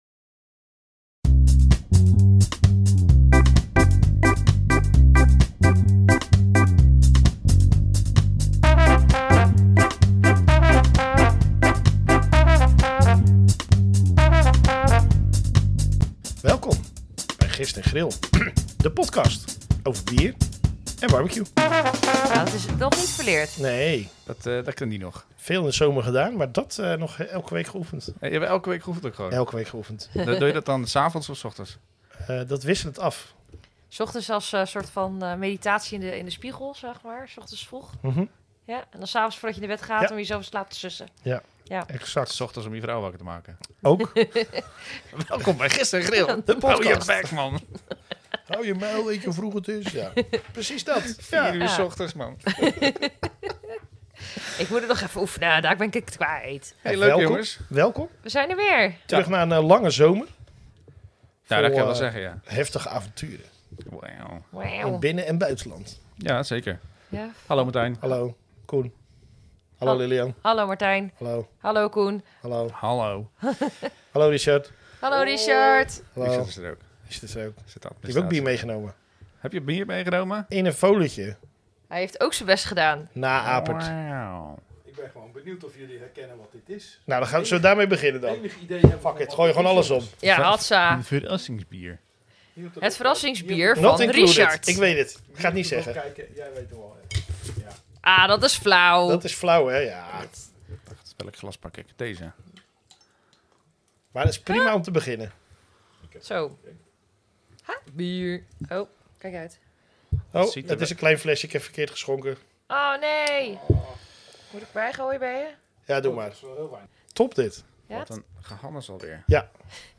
Gist en Grill de Podcast is een Podcast over Bier en BBQ. 3 Amateurs die ook wat te vertellen hebben.